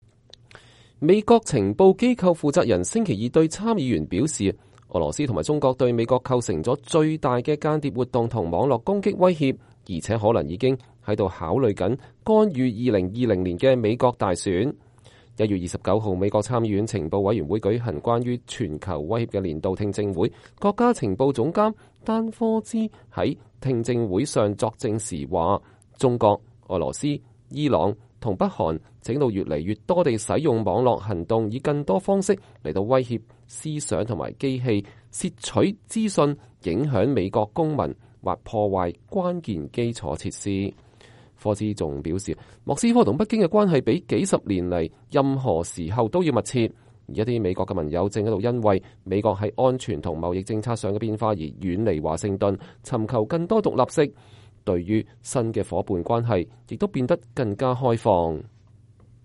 美國國家情報總監丹科茨在聽證會上作證。